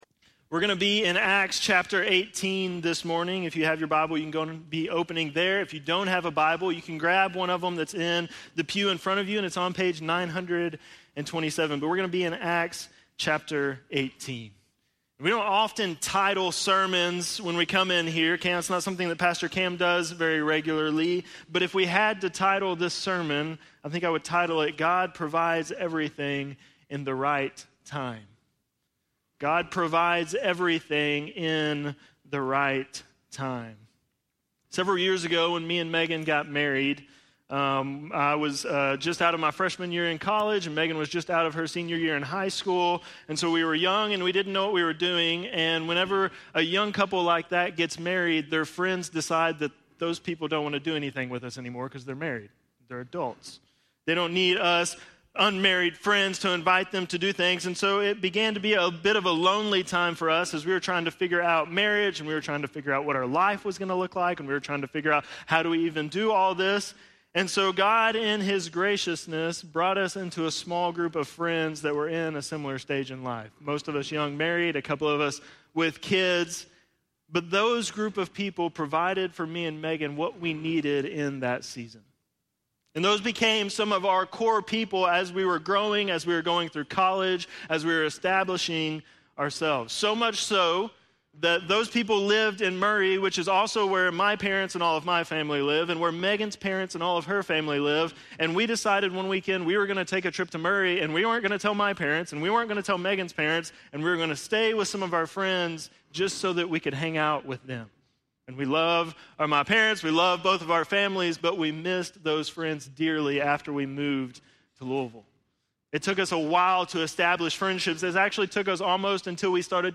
9.29-sermon.mp3